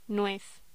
Locución: Nuez